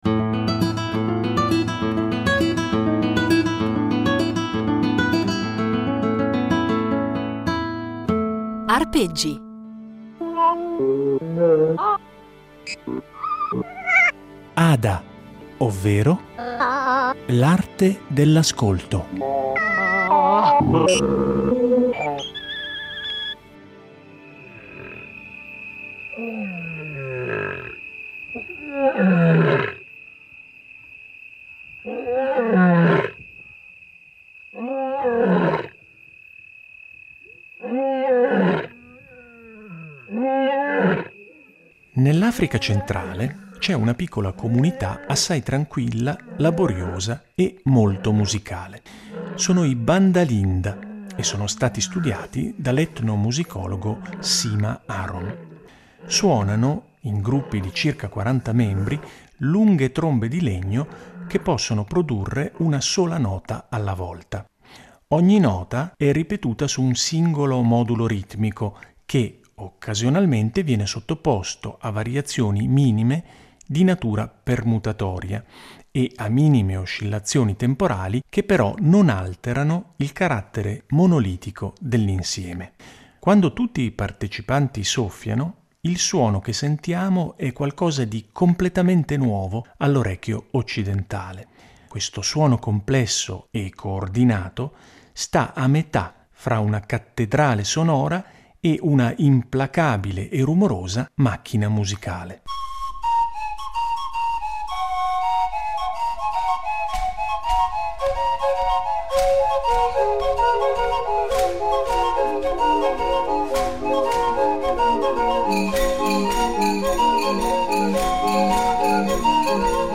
un patchwork fatto di musiche, suoni, rumori e letture